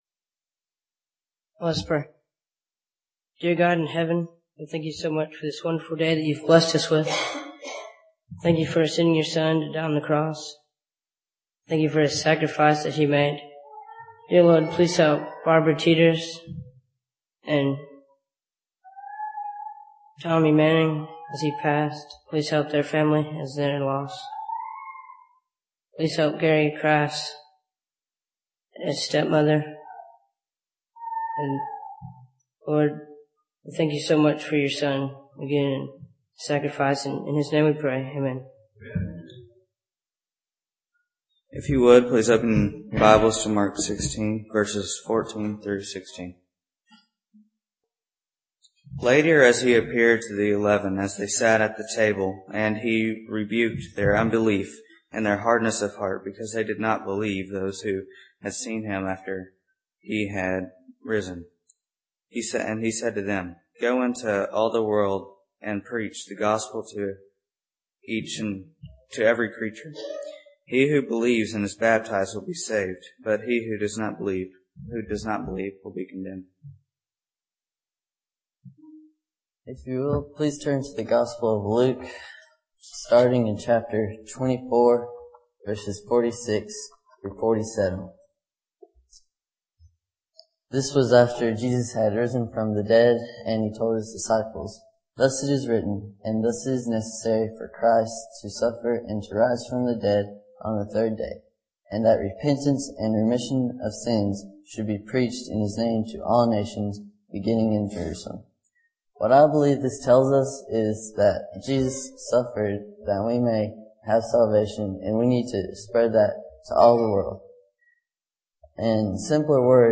Service: Sun PM Type: Sermon Speaker: Various Young Men